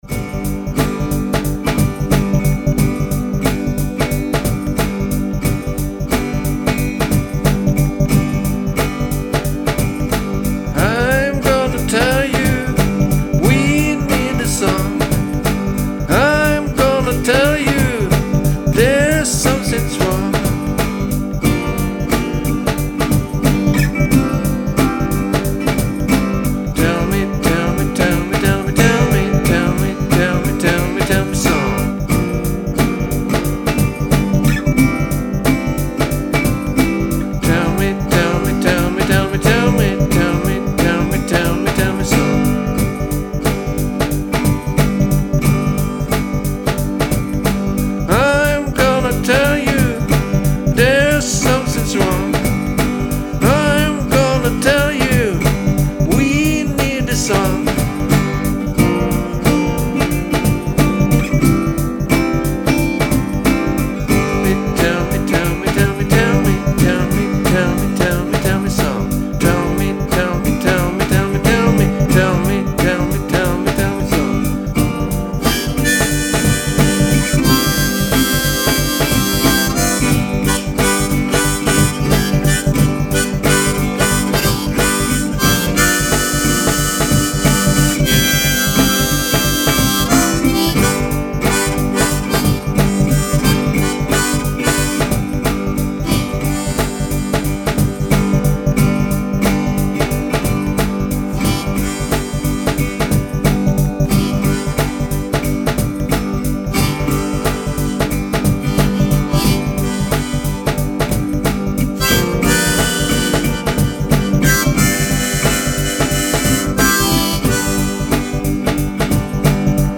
Western guitar with Lyrics, epic.